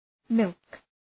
Προφορά
{mılk}